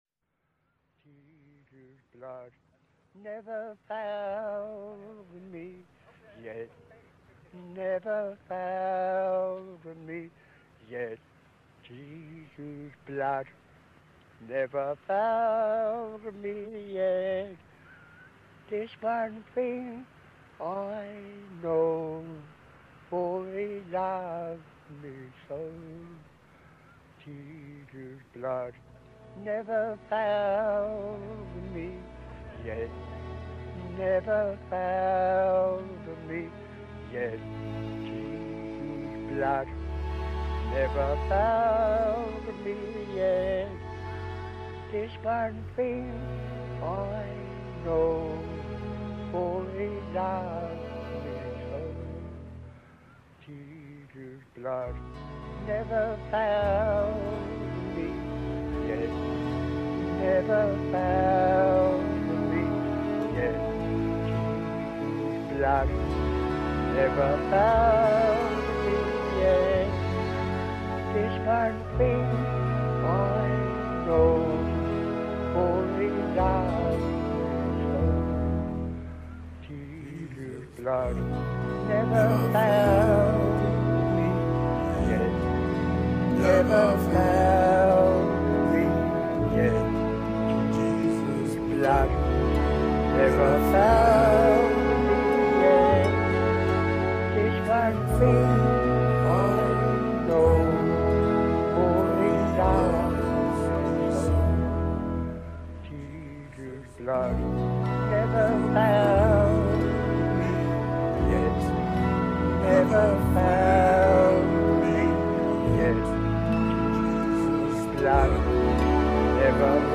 음악 뒤 부분에 나오는 걸쭉한 목소리가 그 입니다.
다른 시대를 산 두 남자의 노래가 섞입니다.
한 목소리는 부랑자의 삶을 살아가면서 아이들이 부르는 단순한 찬양을 읊조리는 노인이고,